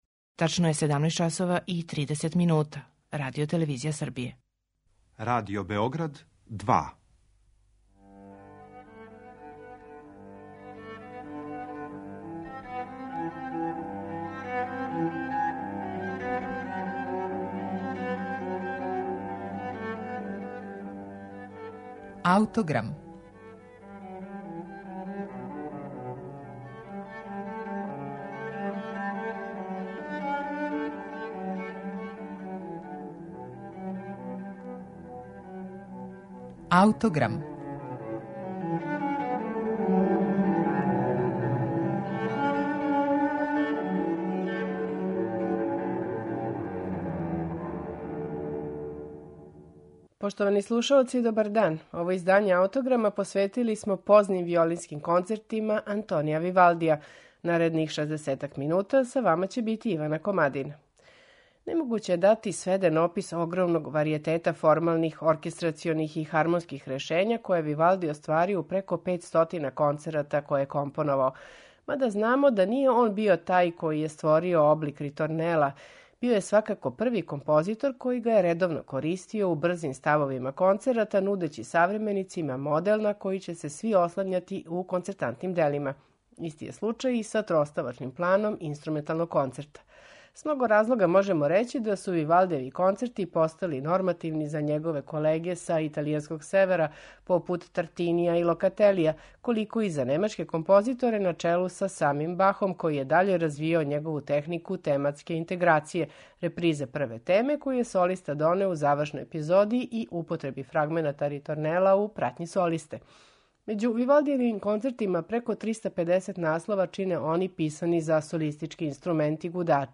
на оригиналним инструментима